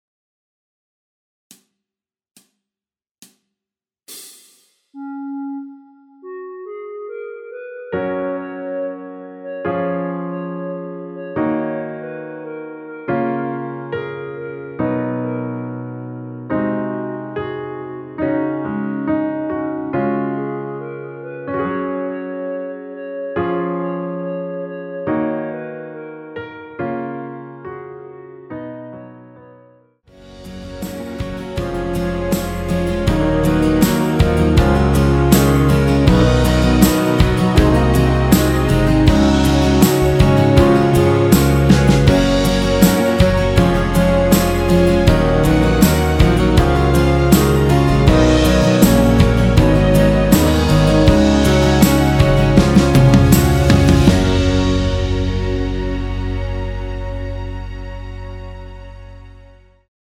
노래가 바로 시작하는곡이라 카운트 넣어 놓았으며
대부분의 남성분이 부르실수 있는키의 MR입니다.
Gb
노래방에서 노래를 부르실때 노래 부분에 가이드 멜로디가 따라 나와서
앞부분30초, 뒷부분30초씩 편집해서 올려 드리고 있습니다.
중간에 음이 끈어지고 다시 나오는 이유는